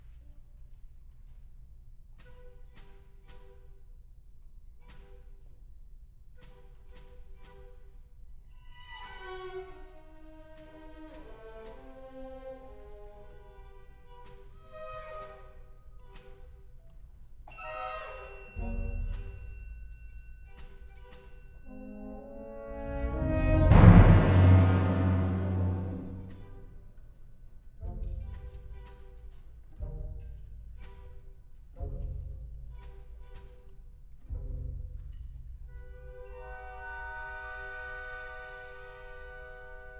fugues for solo piano